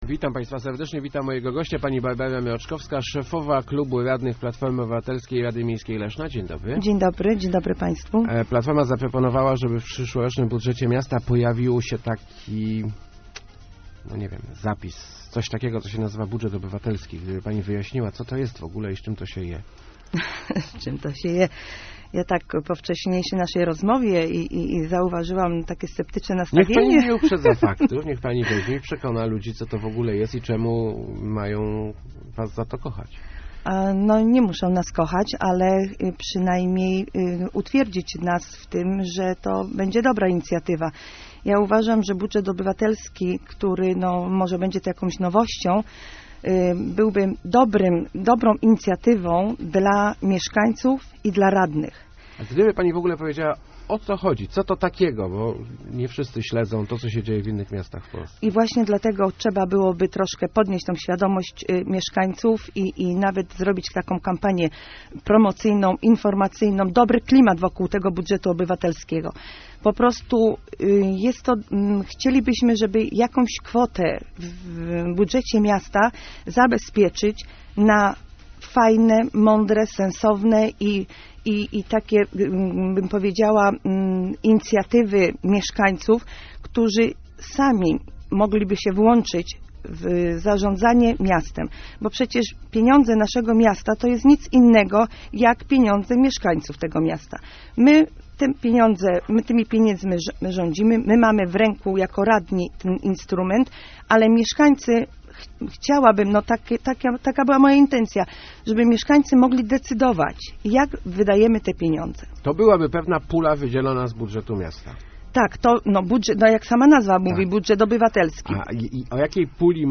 bmroczk.jpg-Nie może być tak, że się mówi o nas bez nas - mówiła w Rozmowach Elki Barbara Mroczkowska, szefowa klubi radnych PO w Radzie Miejskiej Leszna. Właśnie taka jest jej zdaniem idea "budżetu obywatelskiego", czyli puli pieniędzy, która sfinansuje przedsięwzięcia zgłoszone przez samych mieszkańców. Platforma chciałaby taki budżet uruchomić w 2014 roku.